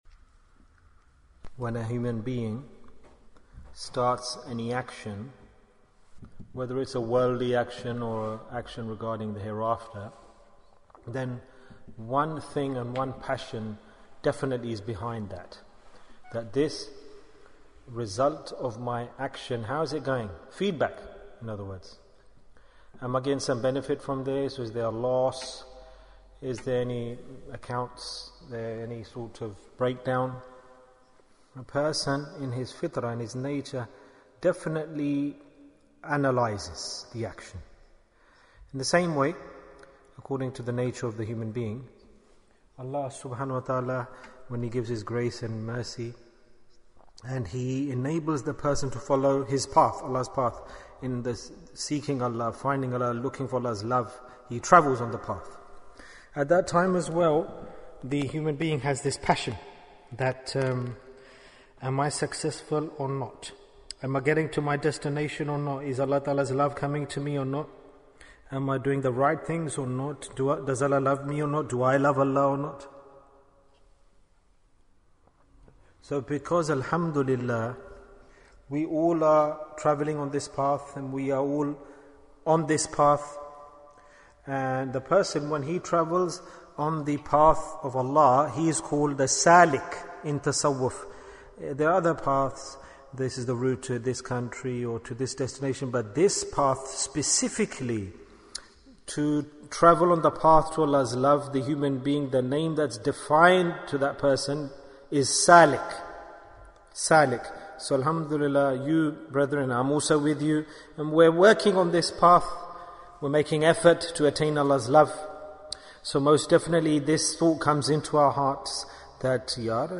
The Sign for Love of Allah Bayan, 35 minutes1st April, 2021